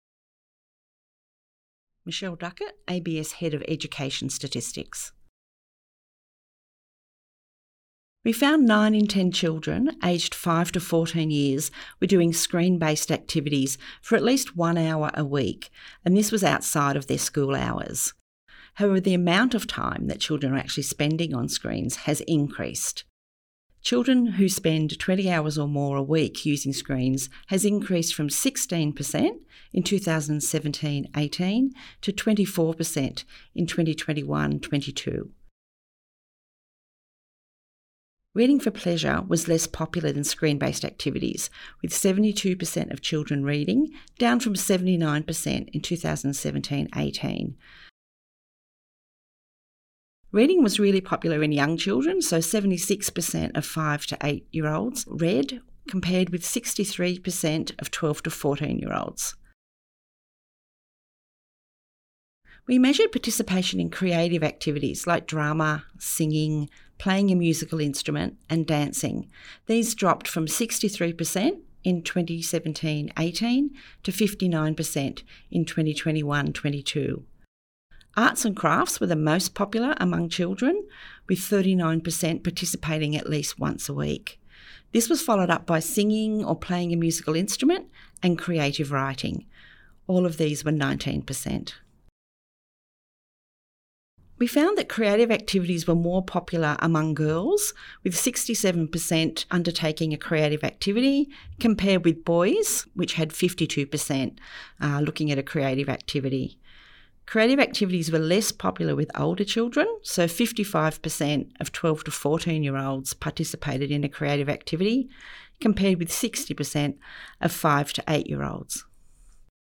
Cultural and creative activities, 2021-22 financial year radio grabs